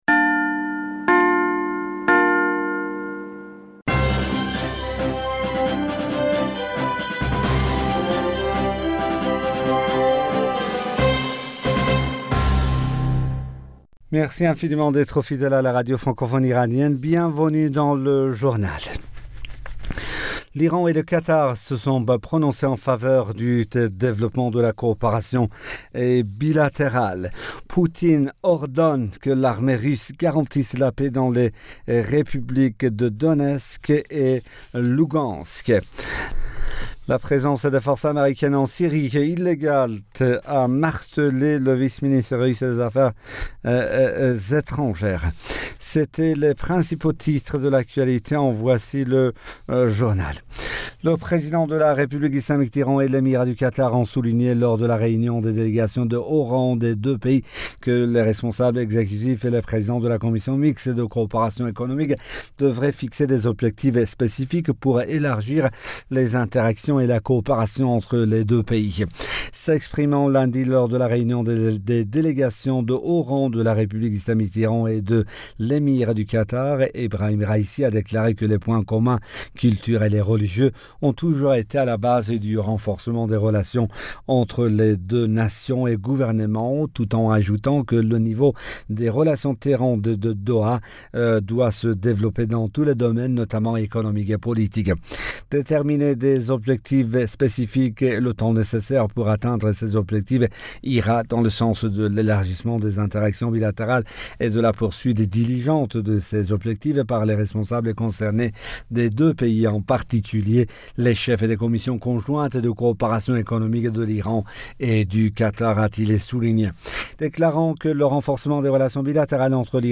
Bulletin d'information Du 22 Fevrier 2022